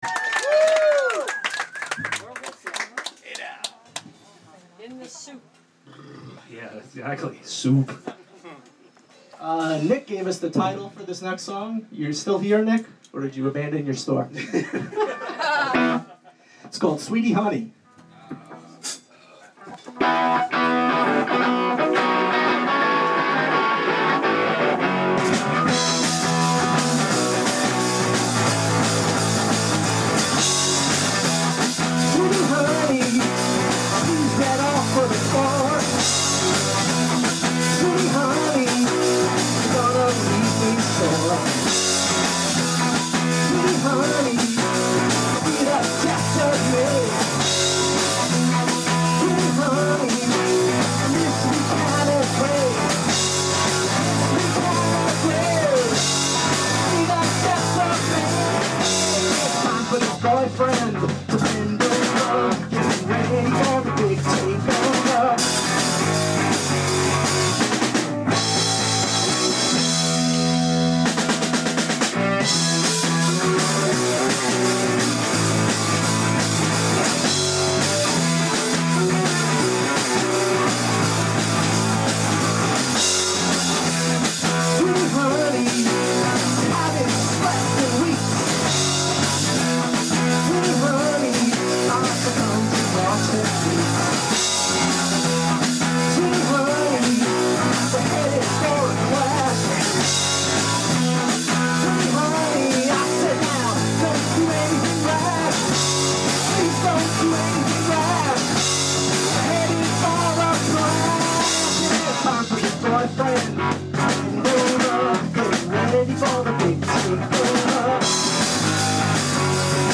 record release party